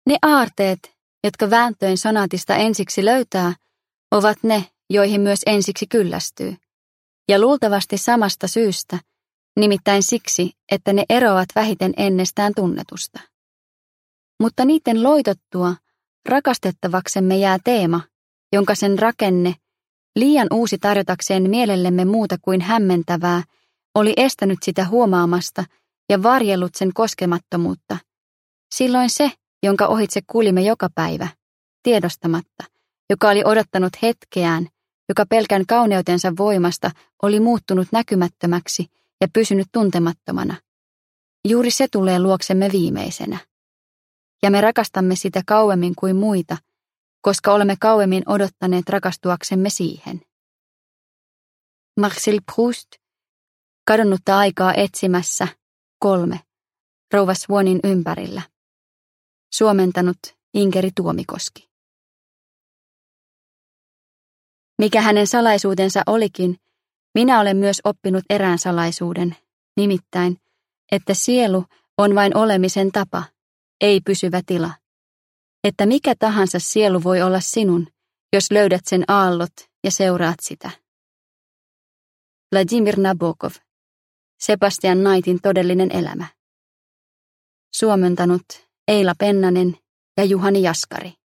Alastonkuvia – Ljudbok – Laddas ner